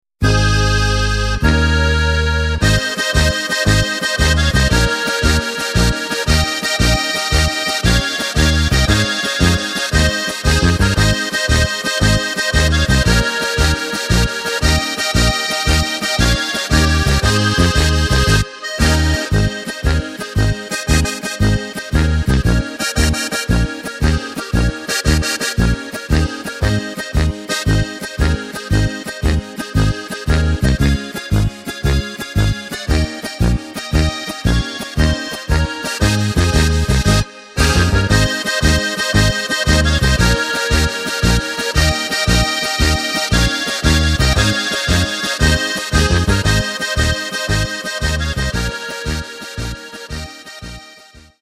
Takt:          2/4
Tempo:         100.00
Tonart:            Ab